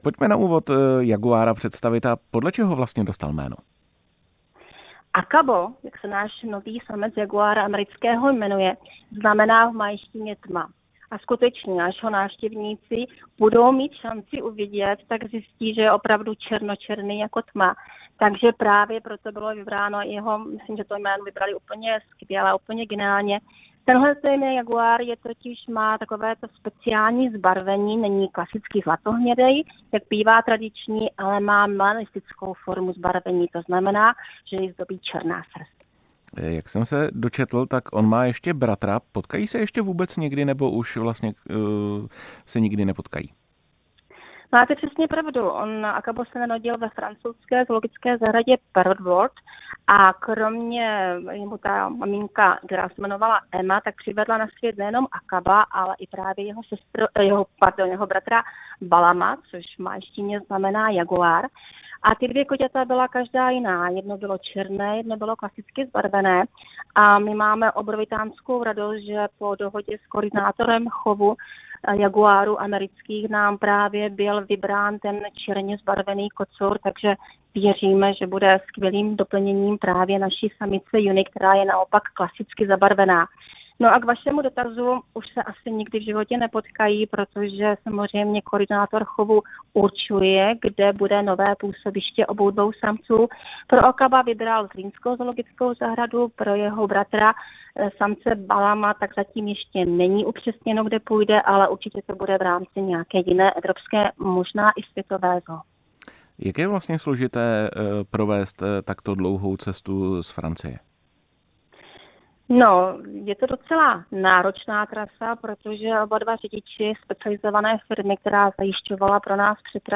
V rozhovoru